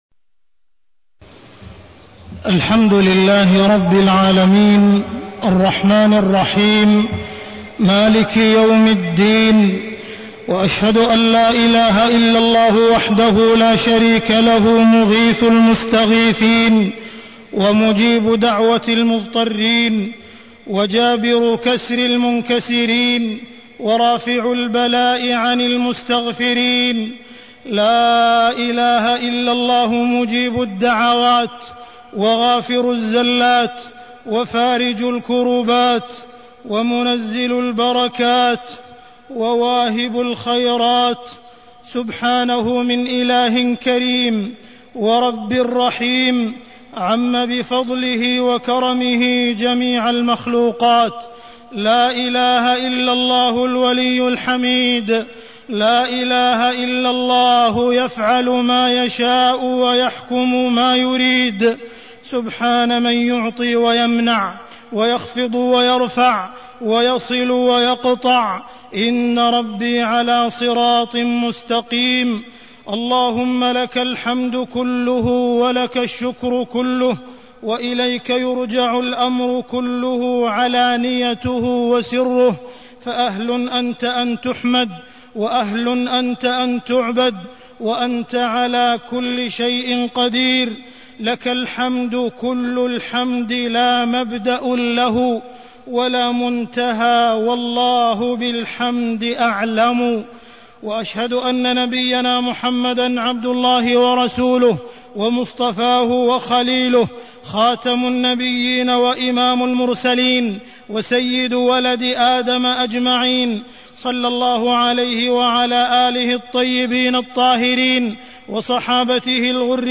تاريخ النشر ١٣ شعبان ١٤٢٢ هـ المكان: المسجد الحرام الشيخ: معالي الشيخ أ.د. عبدالرحمن بن عبدالعزيز السديس معالي الشيخ أ.د. عبدالرحمن بن عبدالعزيز السديس التفكير في عظمة رب الأرباب